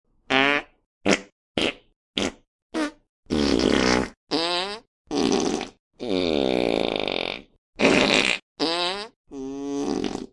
fart.mp3